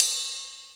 D2 RIDE-08.wav